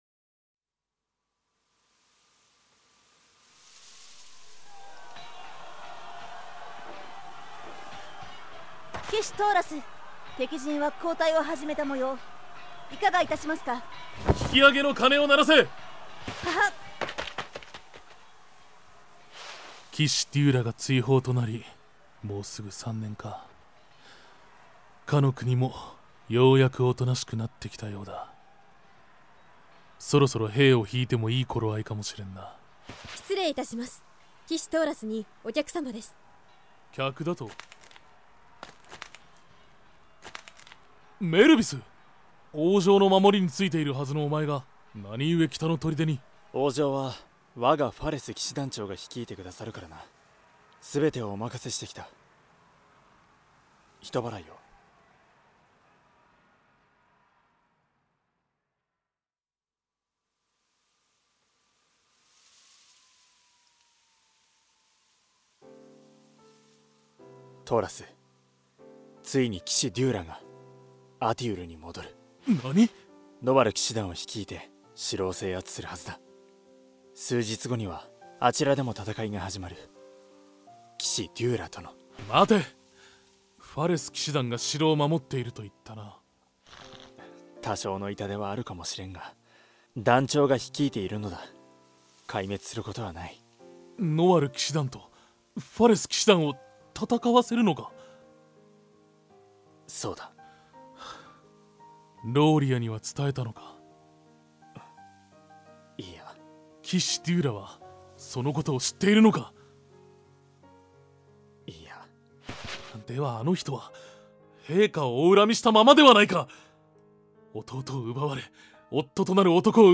Crystal+オリジナルCDドラマ『アティウルの国の物語』